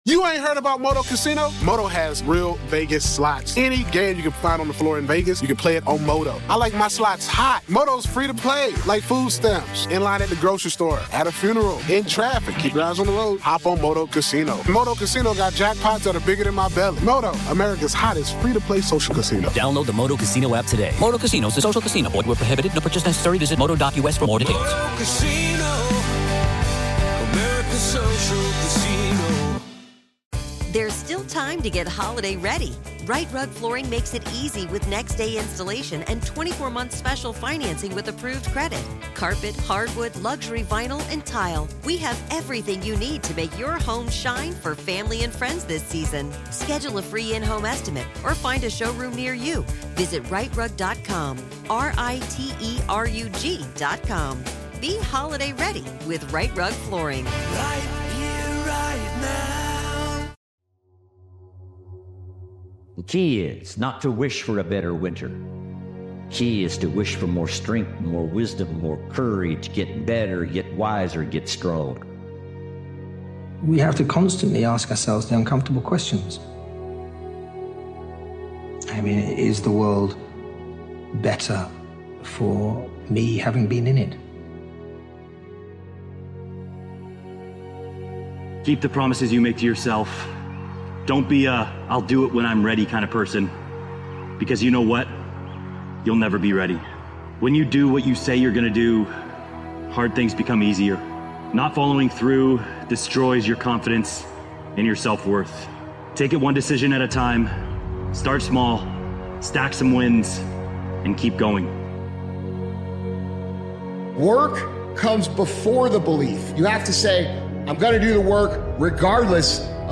We feature the best speakers worldwide and our original motivational speakers. This episode is all about being THAT GUY.